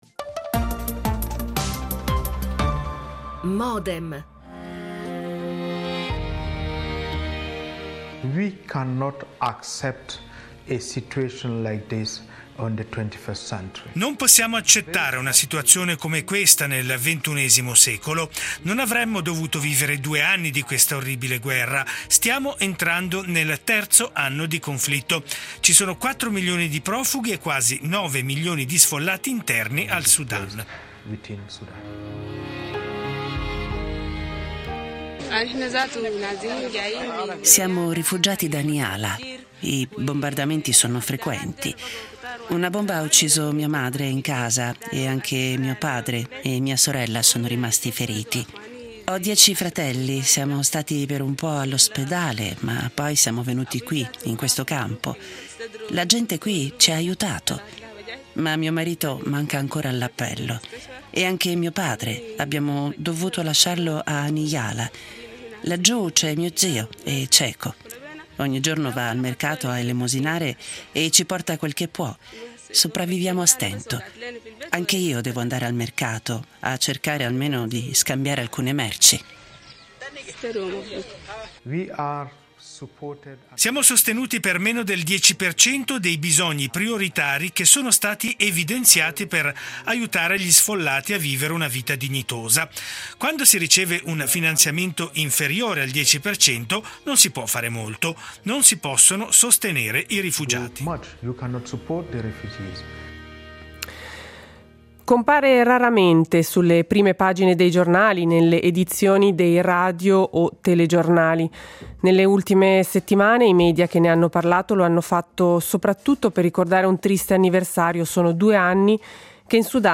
Modem, appuntamento quotidiano (dal lunedì al venerdì) in onda dal 2000, dedicato ai principali temi d’attualità, che vengono analizzati, approfonditi e contestualizzati principalmente attraverso l’apporto ed il confronto di ospiti in diretta.